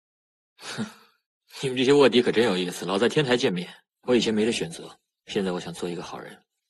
Uduchowiony Głos AI do Narracji Pamiętników
Tekst-na-Mowę
Głębia Emocjonalna
Naturalne Tempo
Narracja Audiobooków